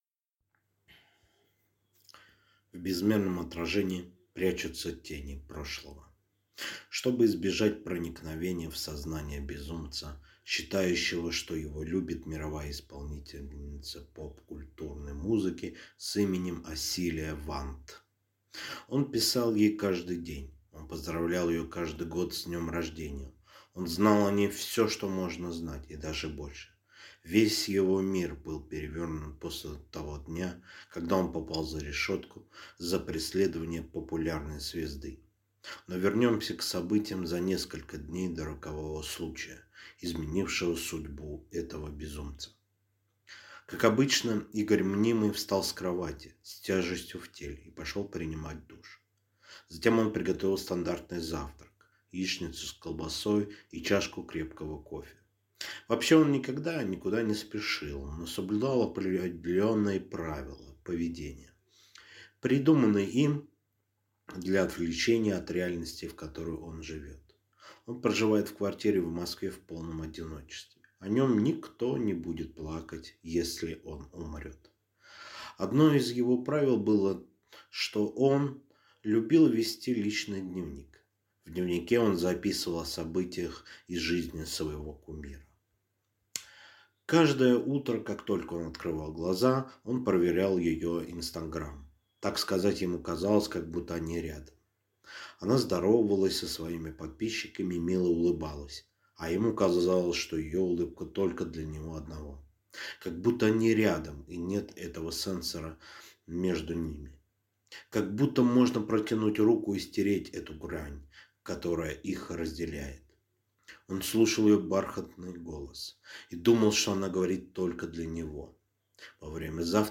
Aудиокнига Харассмент